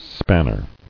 [span·ner]